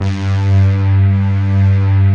Index of /90_sSampleCDs/Club-50 - Foundations Roland/VOX_xScats_Choir/VOX_xSyn Choir 1